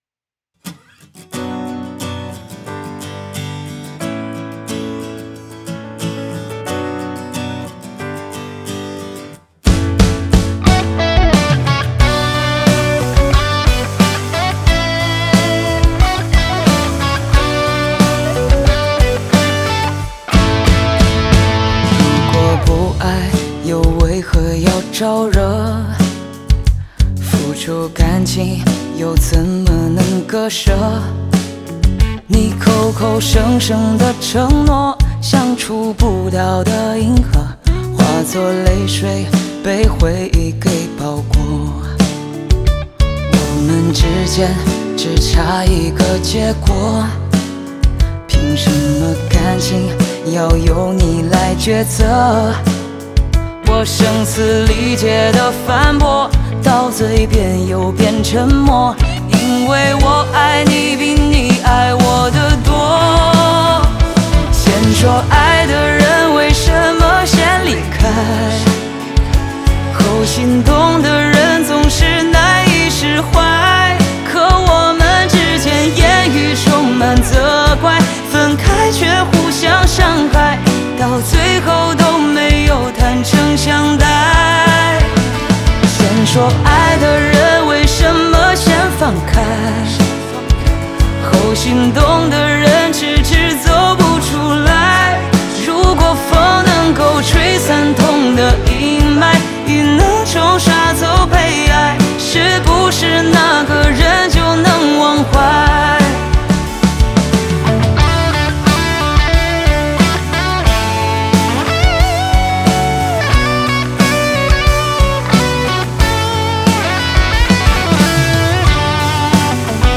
Ps：在线试听为压缩音质节选，体验无损音质请下载完整版
吉他
和音